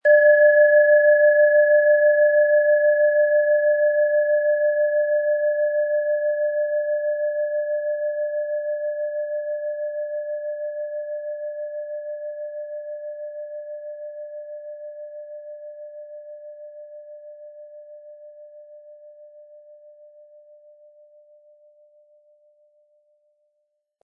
Tibetische Herz-Bauch- und Kopf-Klangschale, Ø 11,1 cm, 180-260 Gramm, mit Klöppel
Im Audio-Player - Jetzt reinhören hören Sie genau den Original-Klang der angebotenen Schale. Wir haben versucht den Ton so authentisch wie machbar aufzunehmen, damit Sie gut wahrnehmen können, wie die Klangschale klingen wird.
Der gratis Klöppel lässt die Schale wohltuend erklingen.